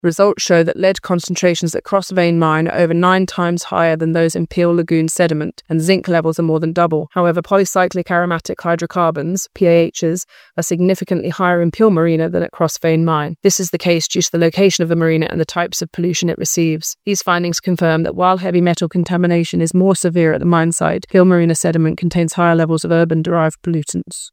Environment Minster, Clare Barber had been asked in House of Keys questions for details of works carried out at Cross Vein Mine - known as 'Snuff the Wind' - on The Round Table.
However, she says it's in different ways: